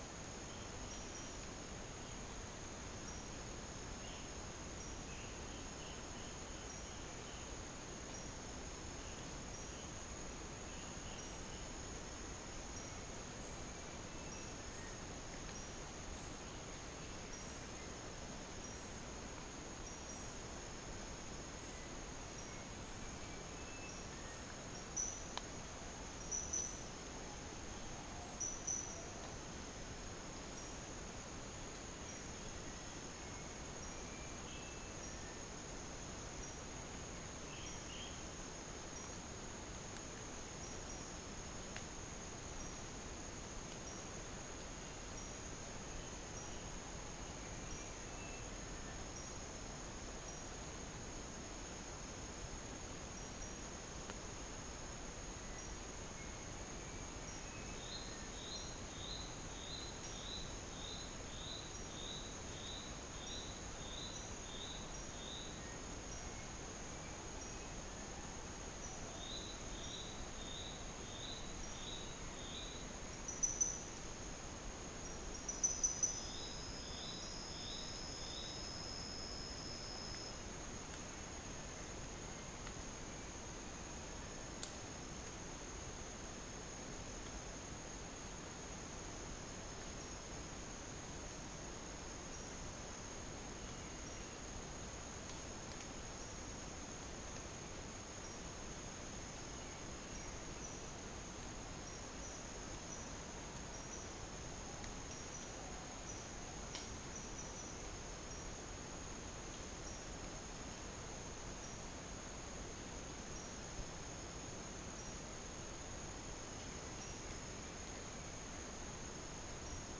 Berbak NP phase 1 and 2
Stachyris maculata 3654 | Pelargopsis capensis 3587 | Cyanoderma erythropterum 3655 | Chrysophlegma miniaceum 3653 | Macronus ptilosus 3588 | Pycnonotus plumosus 3584 | Malacopteron affine 3657 | Arachnothera longirostra 3656 | Psittacula longicauda 3586 | Orthotomus atrogularis 3585 | Dicaeum trigonostigma